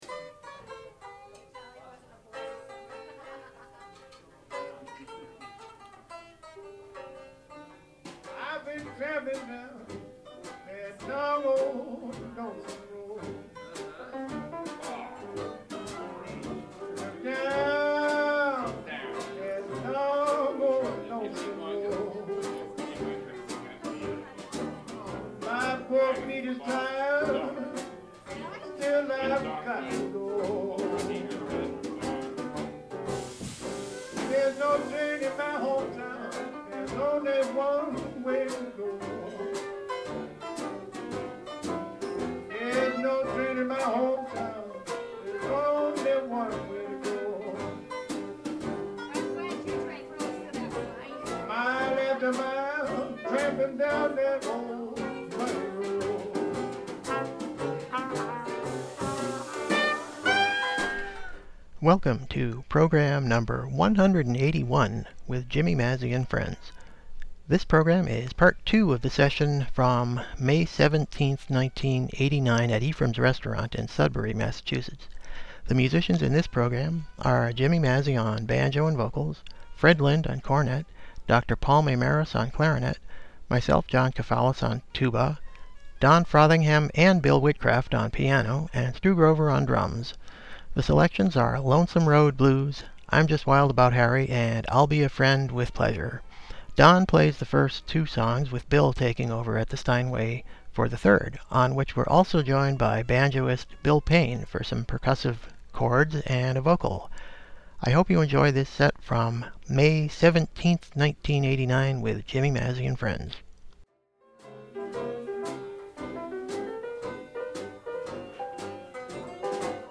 banjo/vocals
cornet
clarinet
tuba
piano
drums